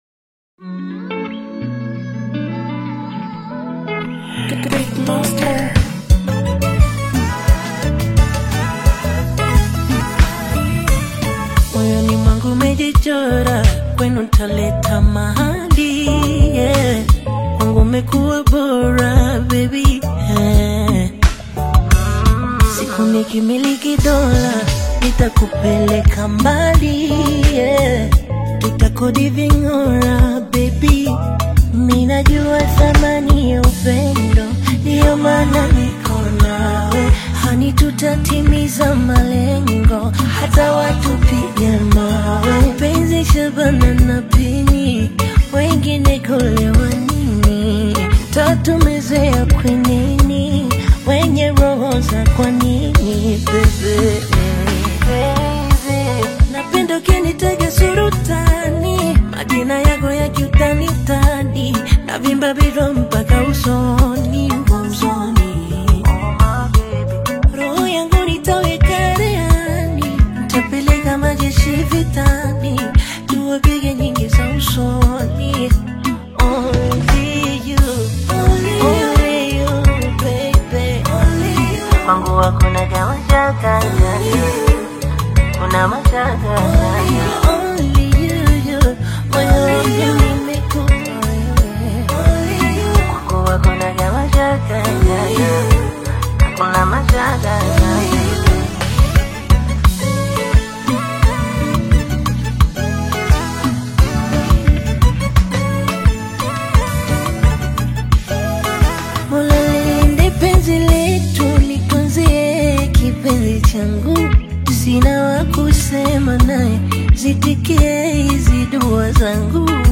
Bongo Fleva
is a heartfelt song
crafting a memorable and relatable love song for listeners.